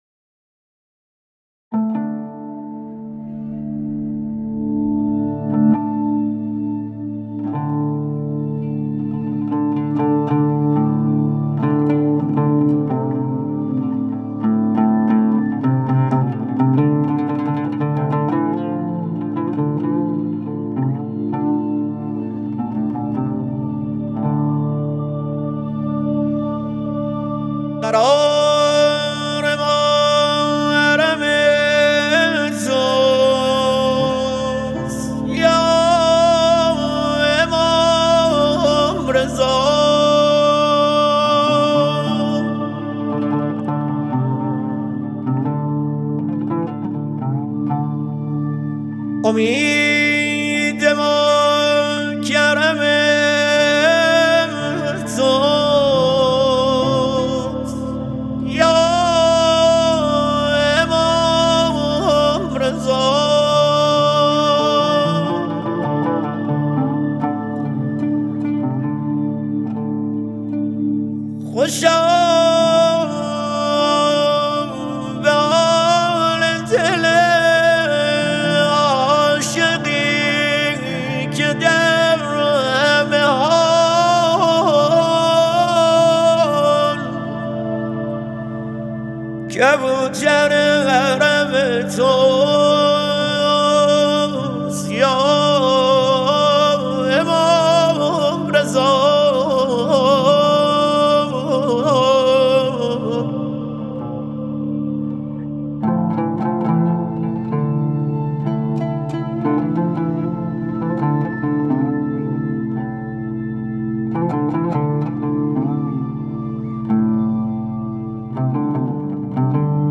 منقبت‌خوانی